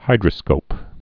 (hīdrə-skōp)